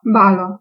Ääntäminen
Synonyymit soirée boîte de nuit boite de nuit bastringue Ääntäminen France: IPA: [bal] Paris Haettu sana löytyi näillä lähdekielillä: ranska Käännös Ääninäyte Substantiivit 1. balo Suku: m .